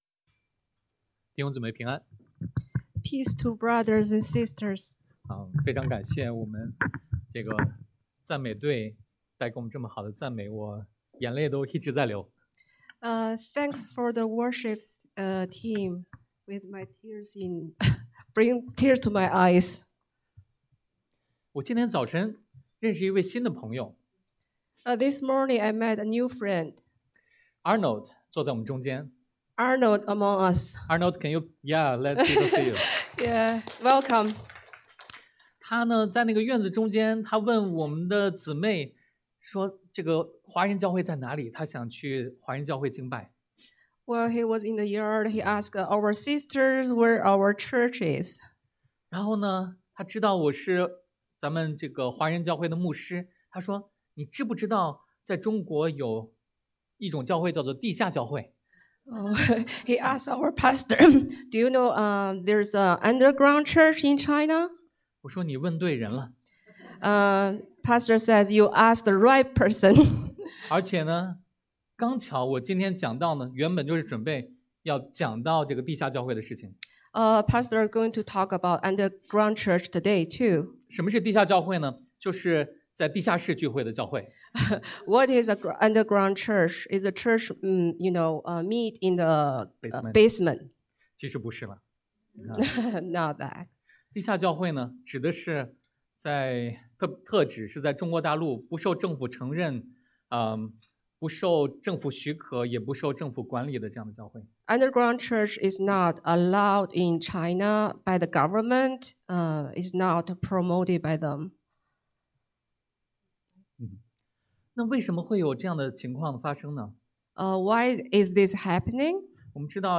Passage: 出埃及记 Exodus 1 Service Type: Sunday AM